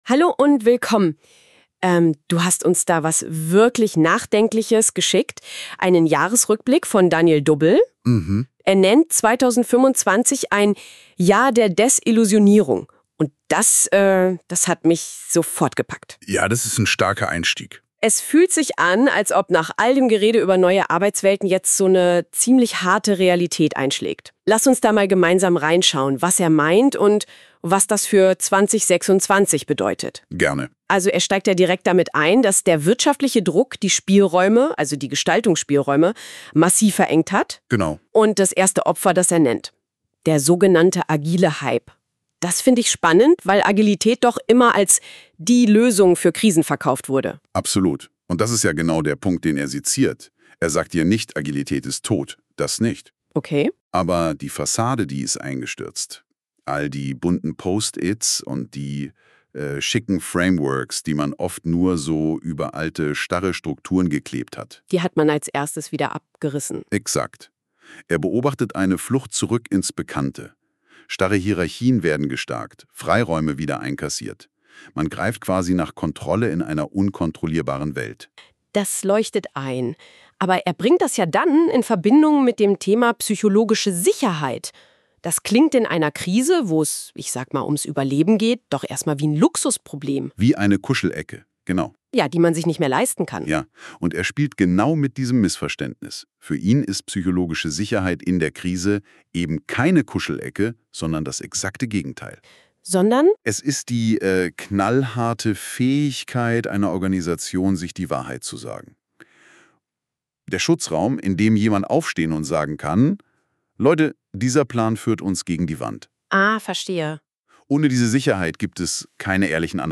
Hier bekommst du einen durch NotebookLM generierten KI-Podcast Dialog zu diesem Artikel.